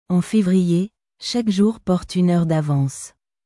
En février, chaque jour porte une heure d’avanceアォン フェヴリエ シャク ジュール ポルトゥ ユ ヌール ダヴァーンス